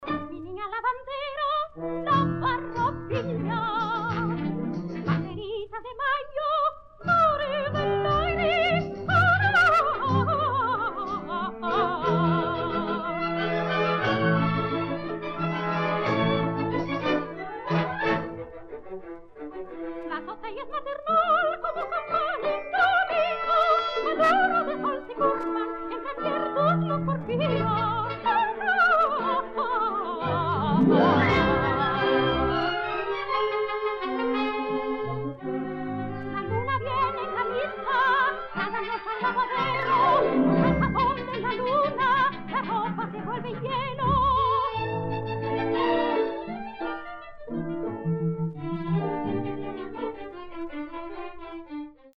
Lyric Soprano